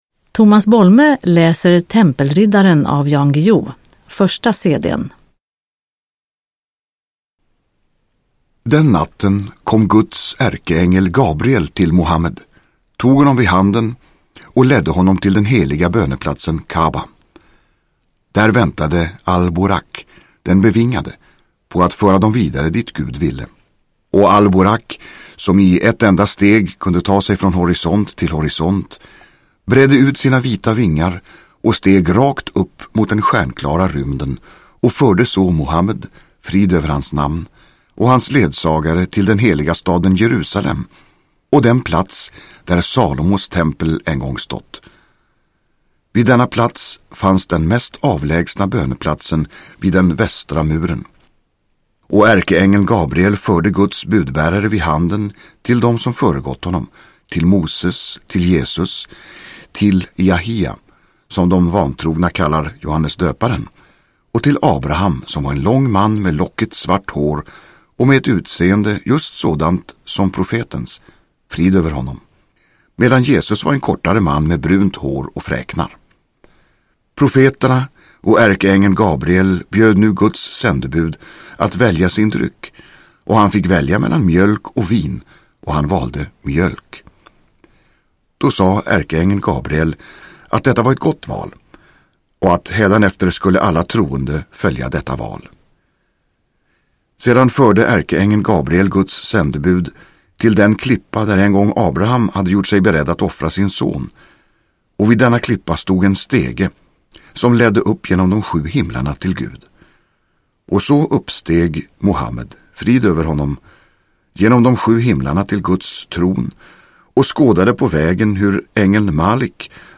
Tempelriddaren / Ljudbok